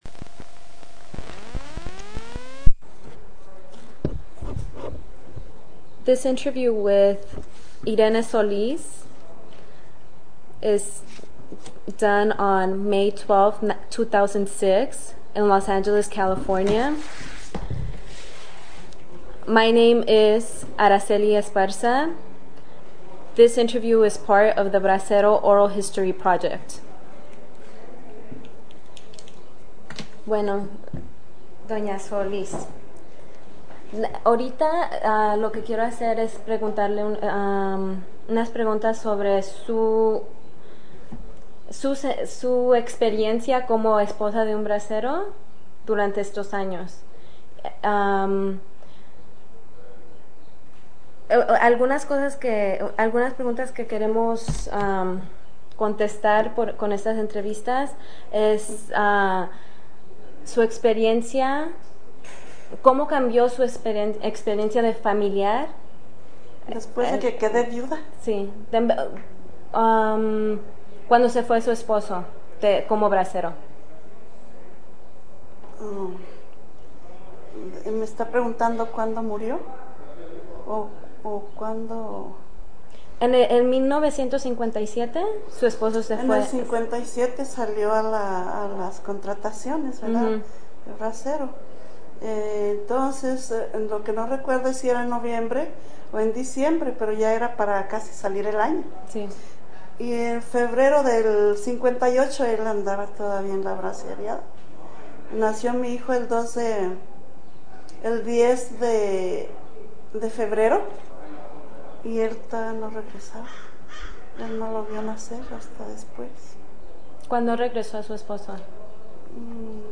Biographical Synopsis of Interviewee